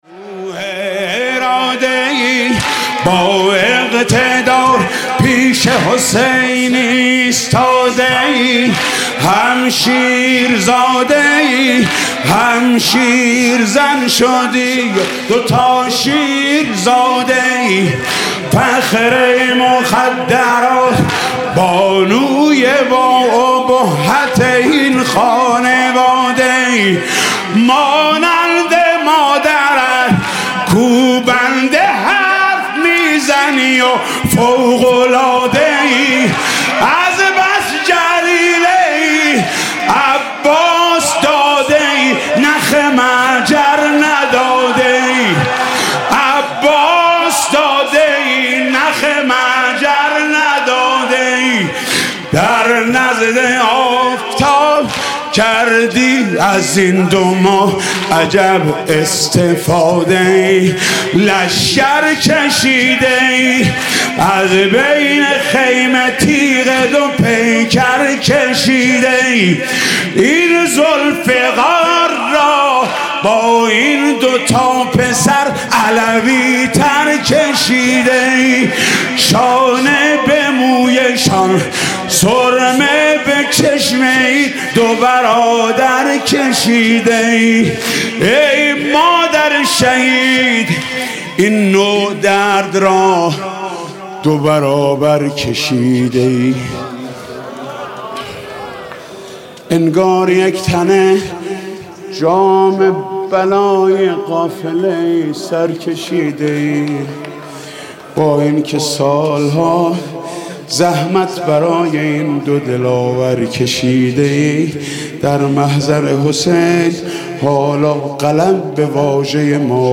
مداحی شب چهارم محرم